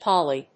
音節Pol・ly 発音記号・読み方
/pάli(米国英語), pˈɔli(英国英語)/